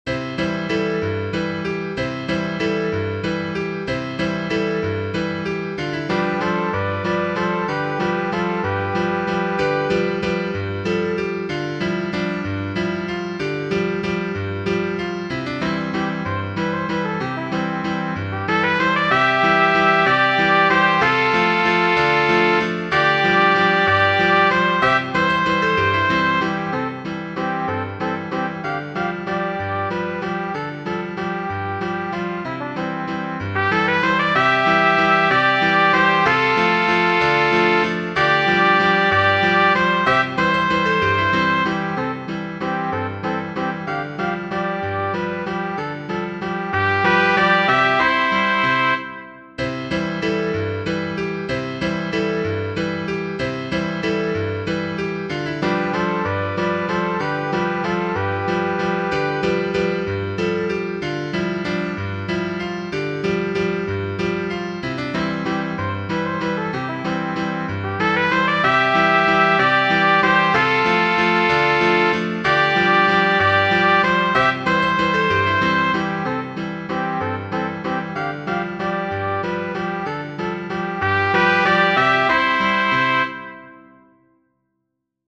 Mendoza y Cortés, Q. Genere: Folk De la Sierra Morena, Cielito lindo, vienen bajando, Un par de ojitos negros, Cielito lindo, de contrabando.